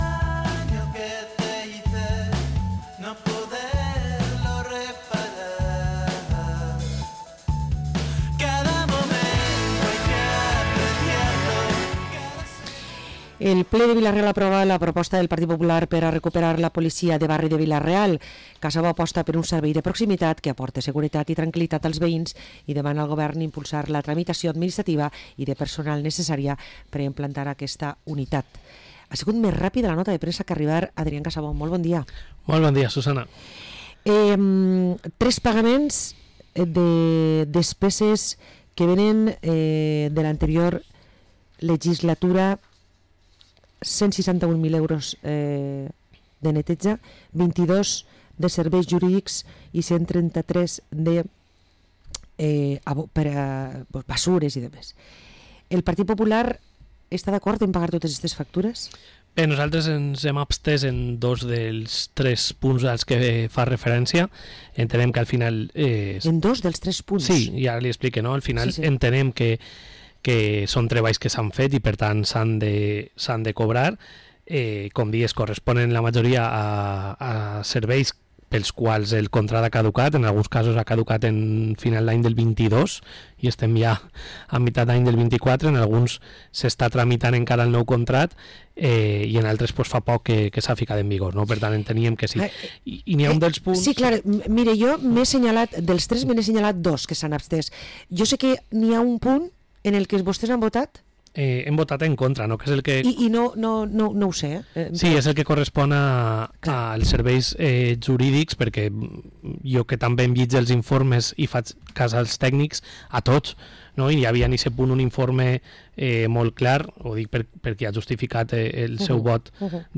Parlem amb Adrián Casabó, portaveu i regidor del PP a Vila-real